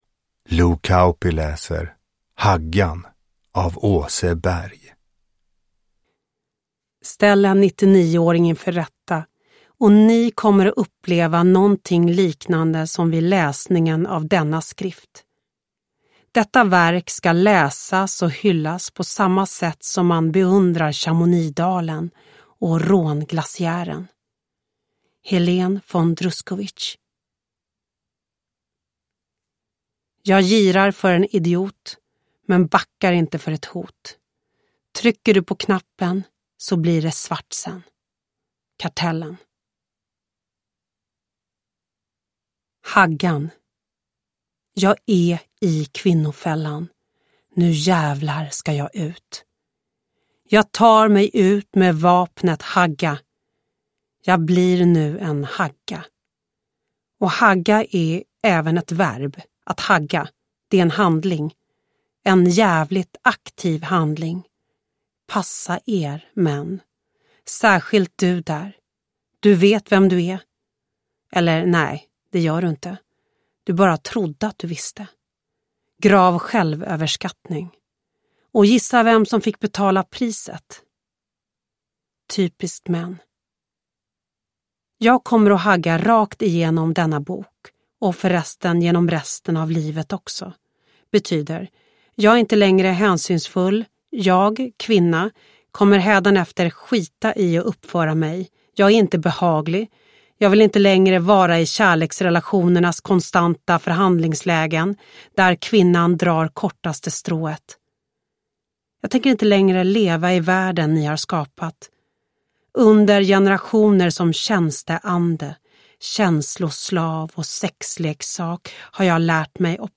Uppläsare: Lo Kauppi
Ljudbok